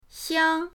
xiang1.mp3